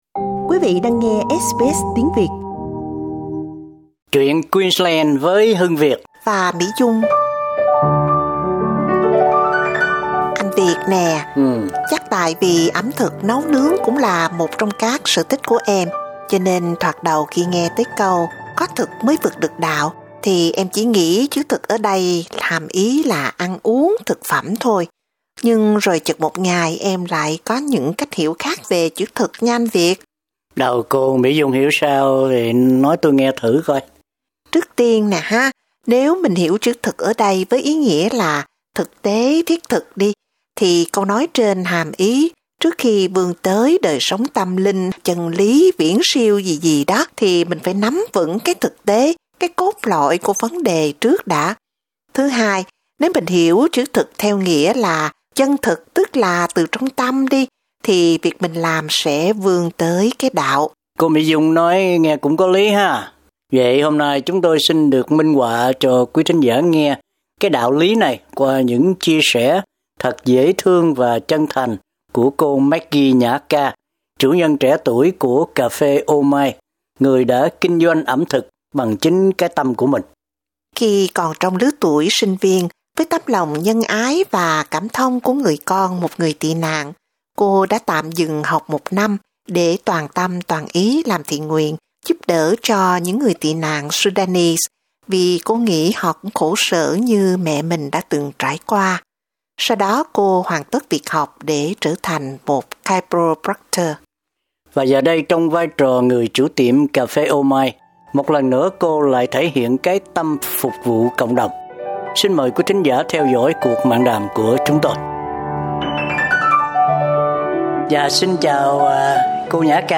Xin mời quý thính giả theo dõi cuộc mạn đàm của chúng tôi.